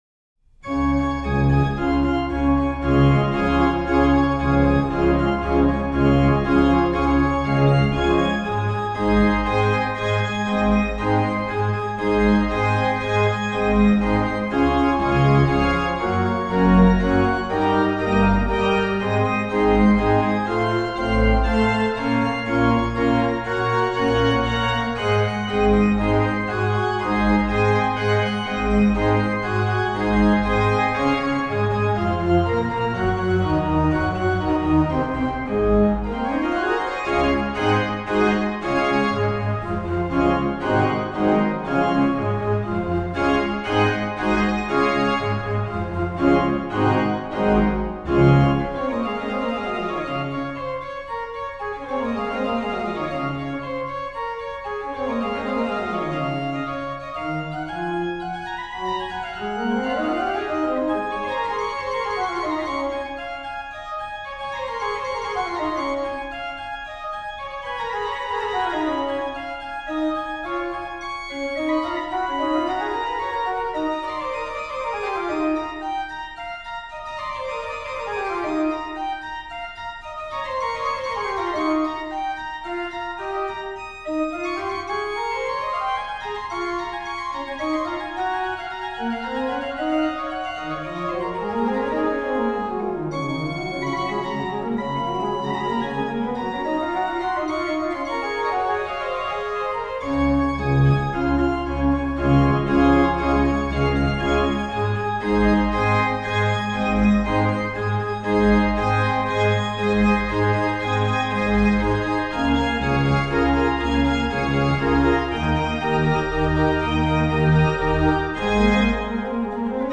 Venue   1717 Trost organ, St. Walpurgis, Großengottern, Germany
MAN: Pr8, Oct4, Qnt3, Oct2
PED: Sub16, Oct8, Oct4, Tr8